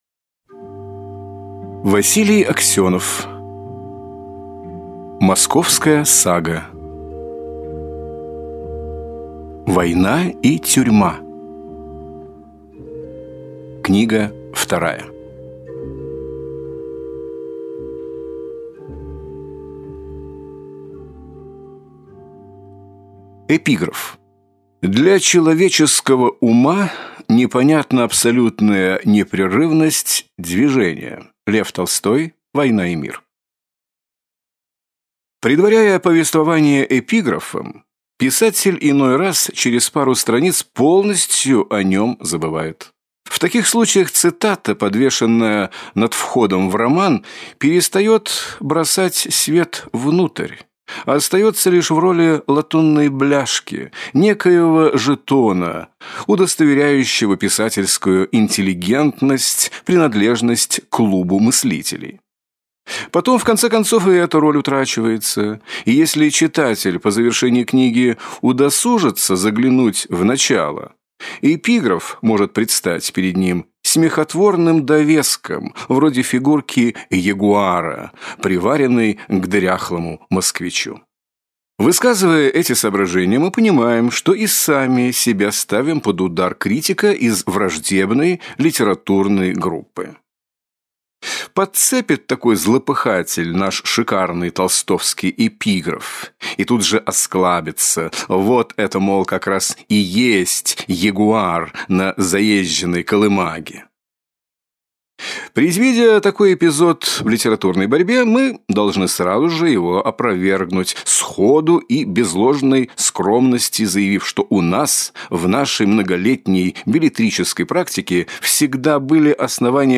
Аудиокнига Московская сага. Война и тюрьма. Книга 2 | Библиотека аудиокниг